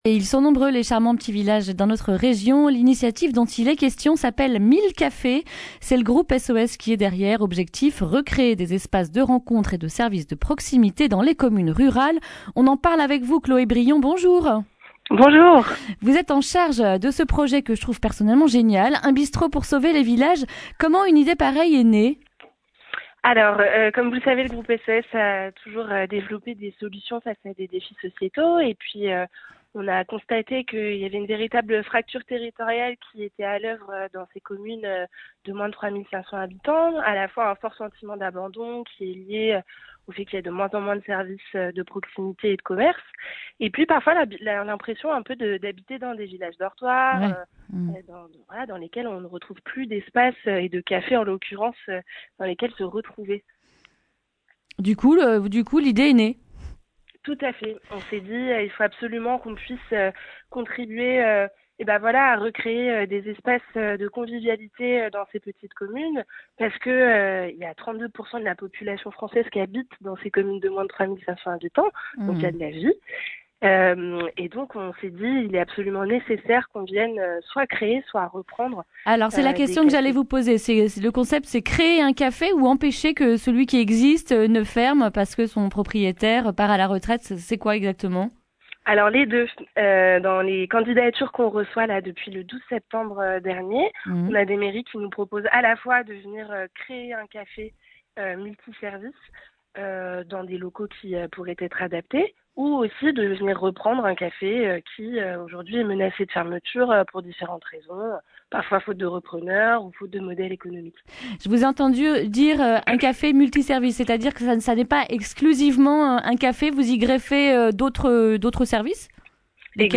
jeudi 10 octobre 2019 Le grand entretien Durée 10 min